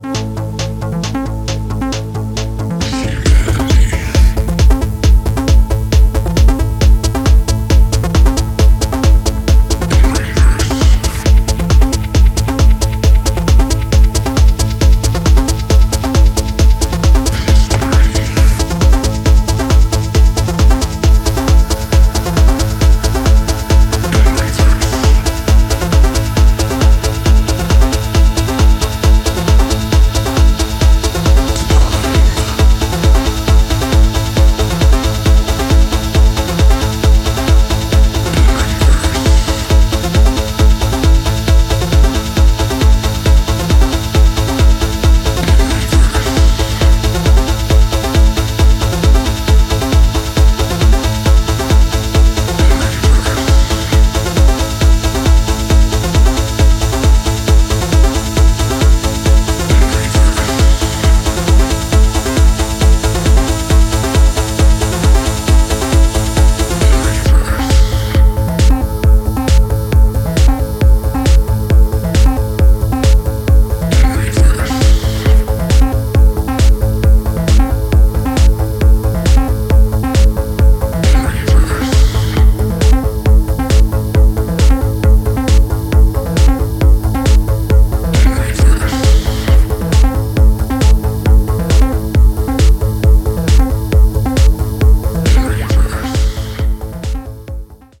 研ぎ澄まされた音響センス、パワフルなグルーヴ、流石の完成度です。